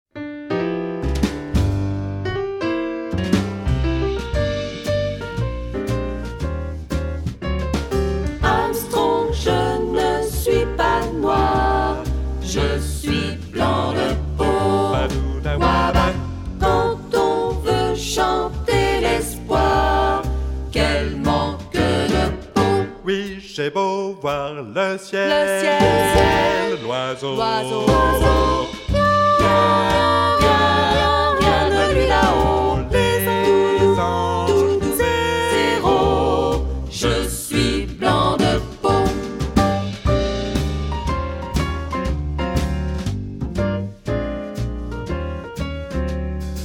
15 tracks arranged for mixed choir and jazz trio